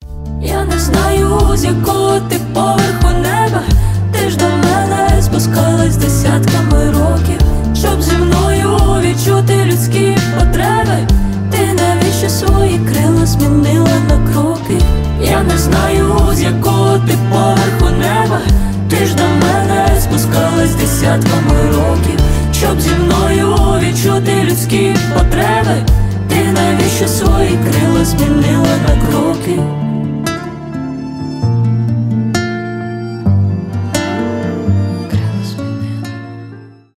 лирика , дуэт
поп